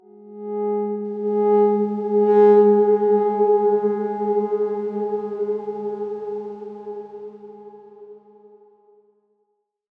X_Darkswarm-G#3-pp.wav